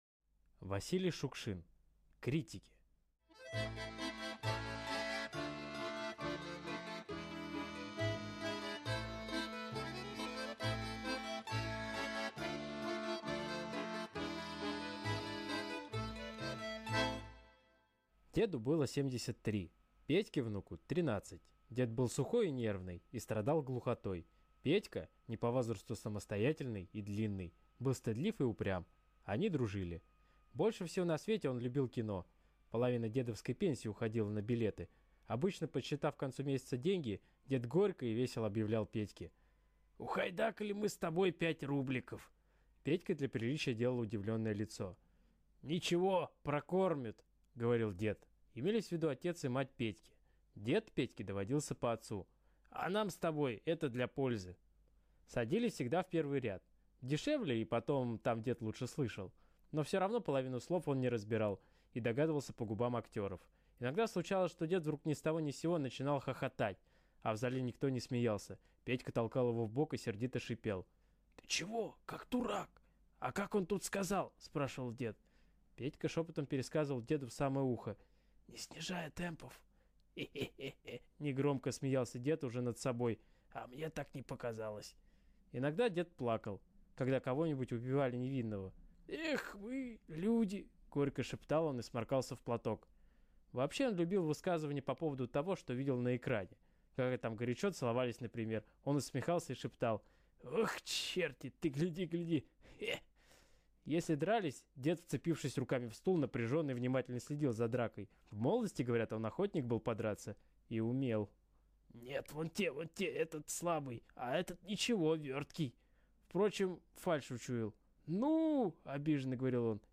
Критики - аудио рассказ Шукшина В.М. Рассказ про Петьку - тринадцатилетнего паренька и его деда - семидесятитрехлетнего глуховатого старика.